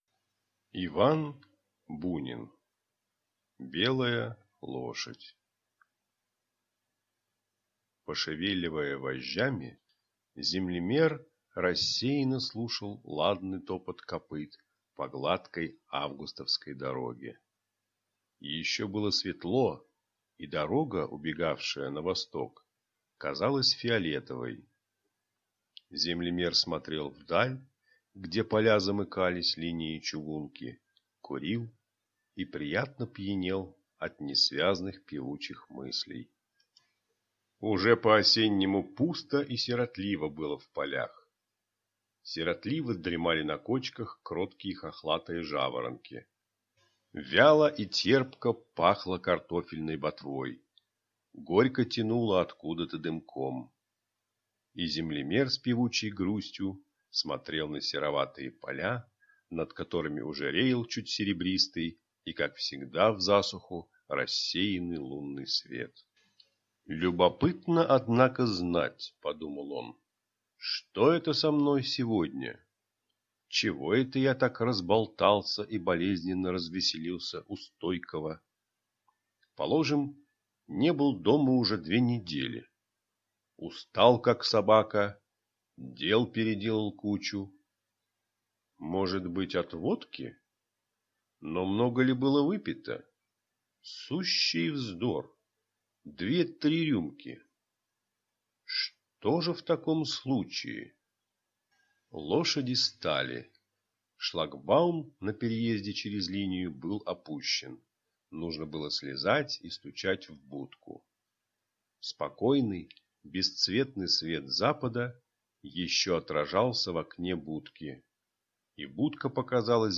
Аудиокнига Белая лошадь | Библиотека аудиокниг